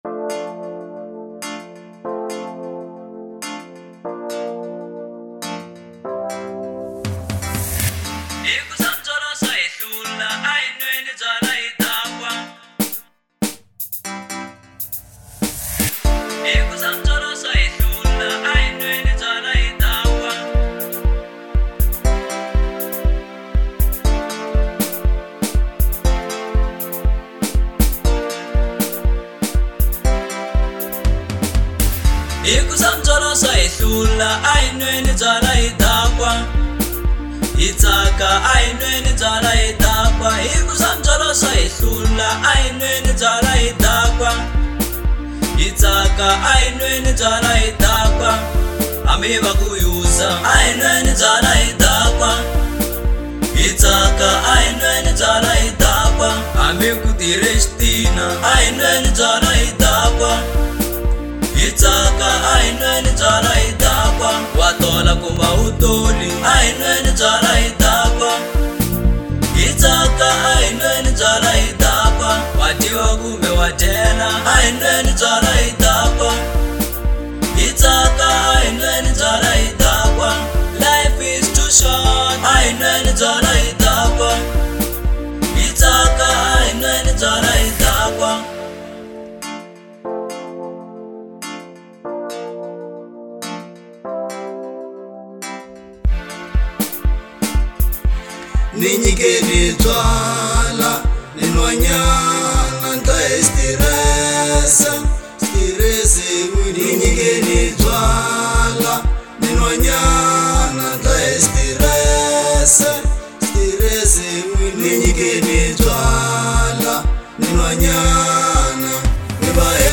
04:11 Genre : Local House Size